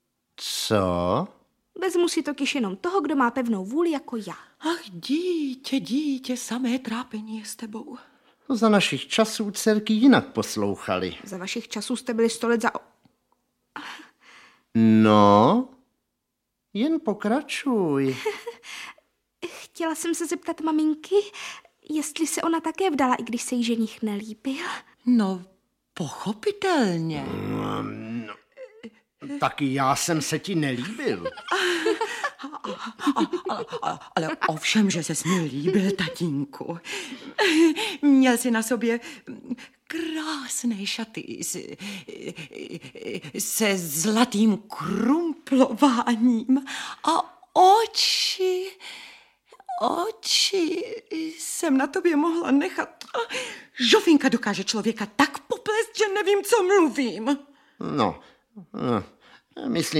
Audiobook
Read: Marek Eben